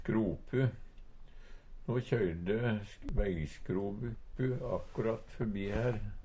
skropu - Numedalsmål (en-US)